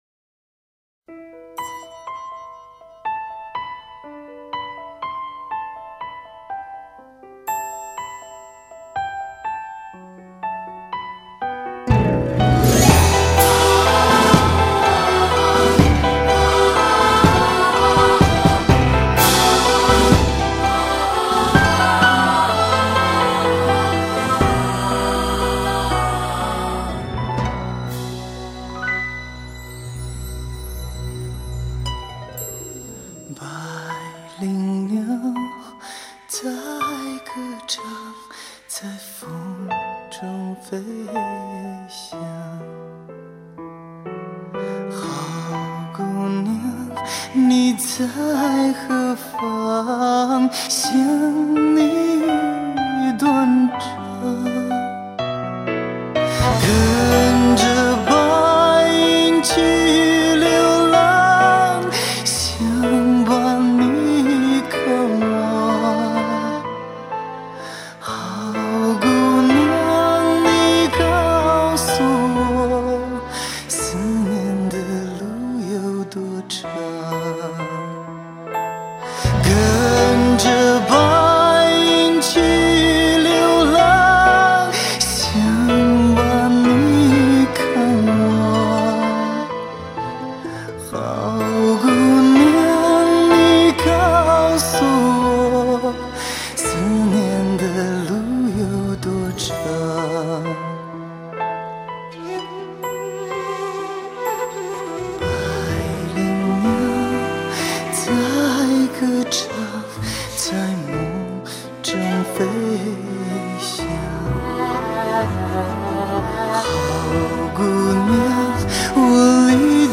[8/9/2010]新疆民歌《百灵鸟》